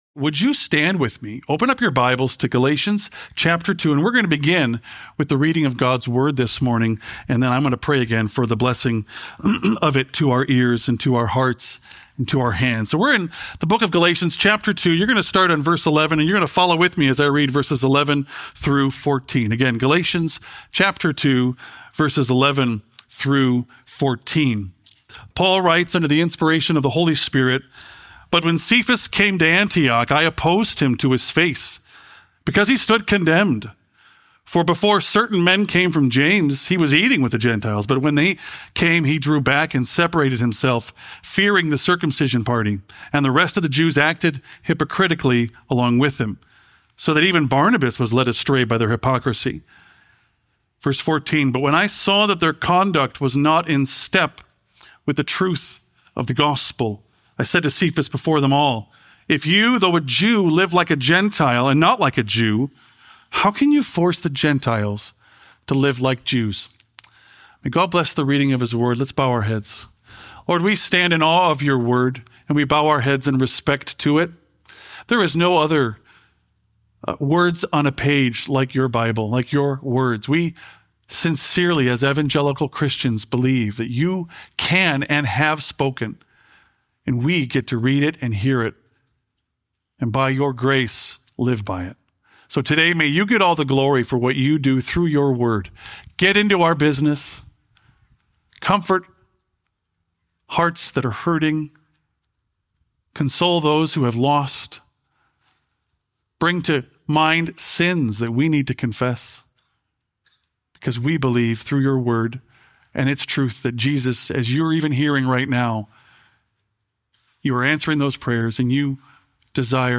This sermon explores how God has placed each of us in our own time and place with purpose.